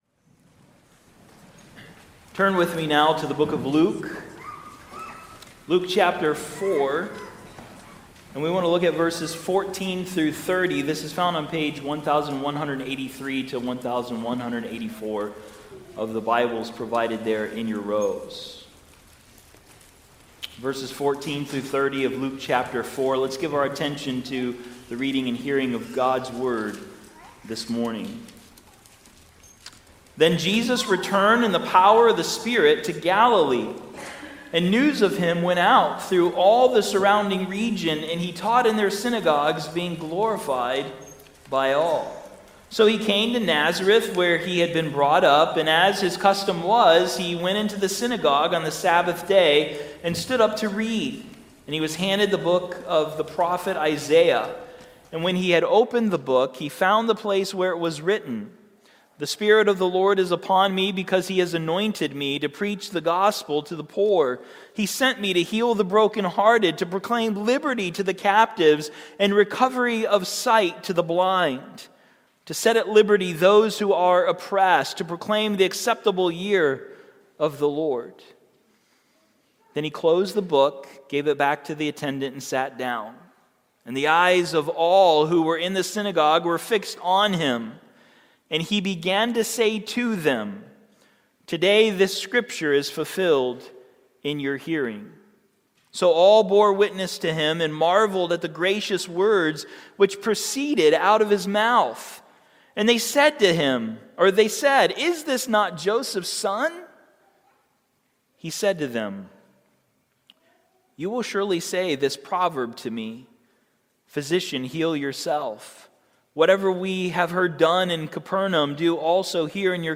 Honored, Heard and Hated | SermonAudio Broadcaster is Live View the Live Stream Share this sermon Disabled by adblocker Copy URL Copied!